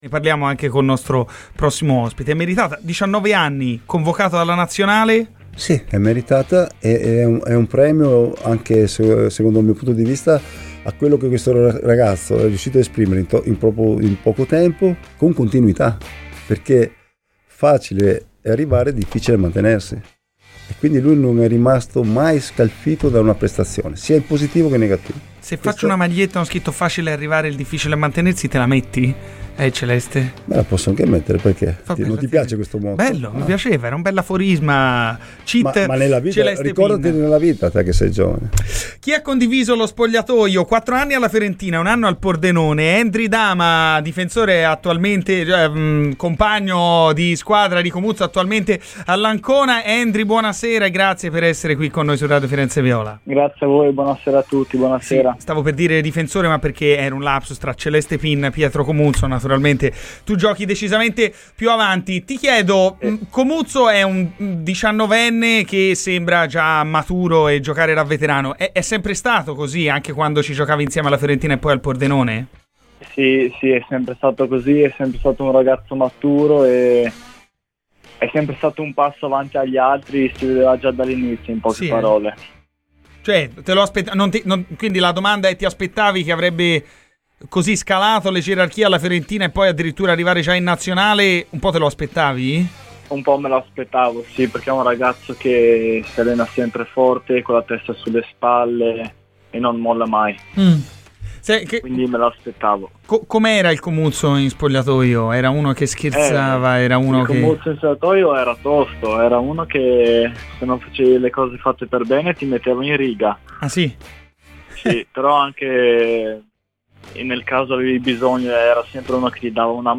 ha parlato a Radio FirenzeViola durante la trasmissione "Garrisca il Vento."